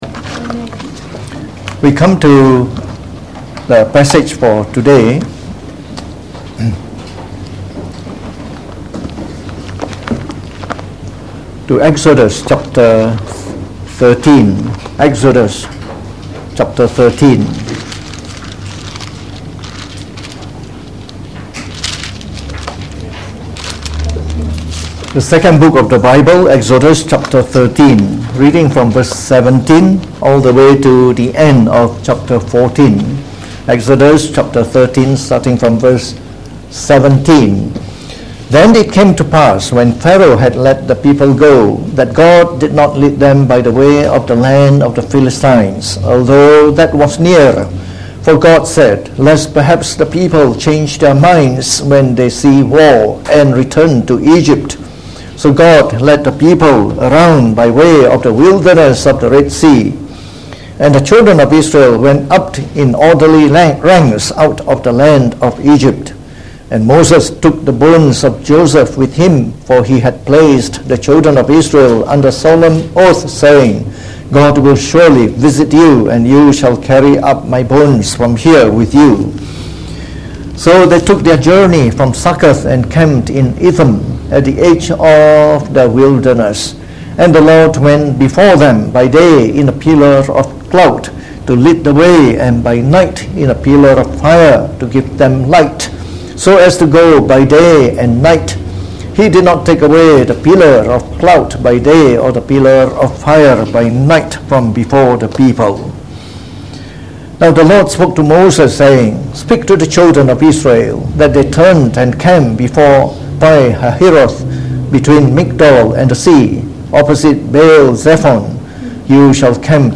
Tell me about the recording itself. delivered in the Morning Service